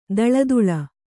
♪ daḷaduḷa